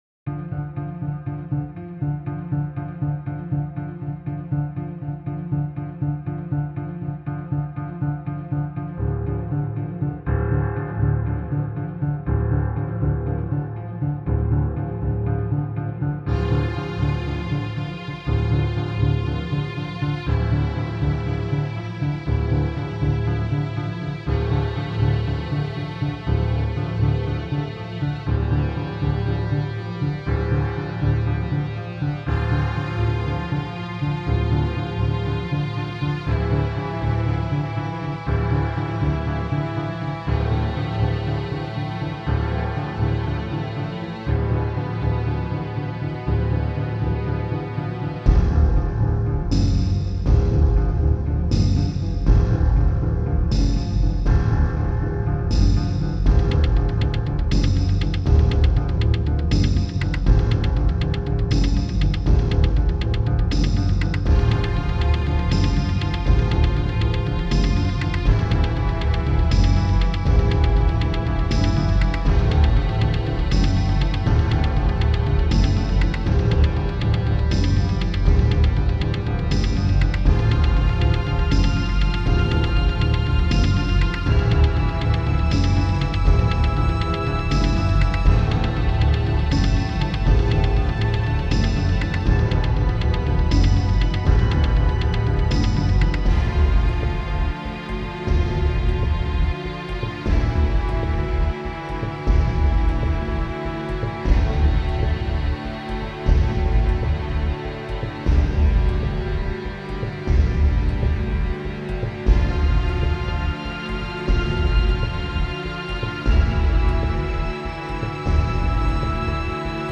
Horror, threat and sadness in menacing places
Incessant pounding criminal activity.      mp3